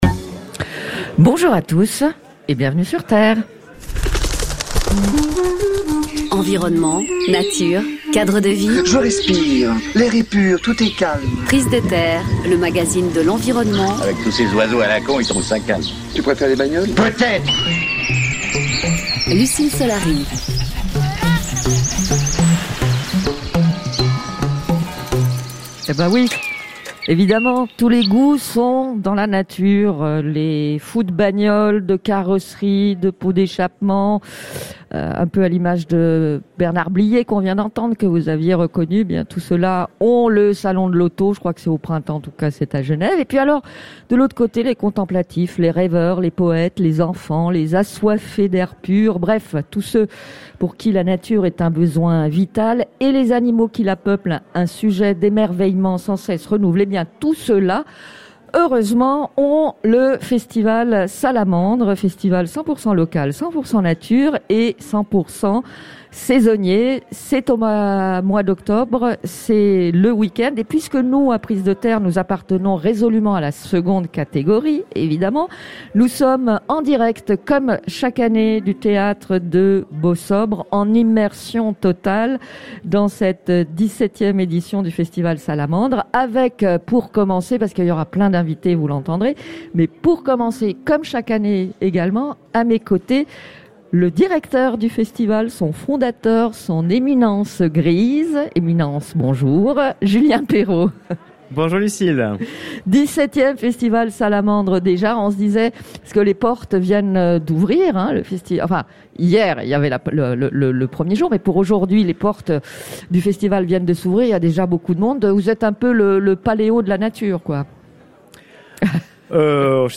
Emission RTS Prise de Terre Festival Salamandre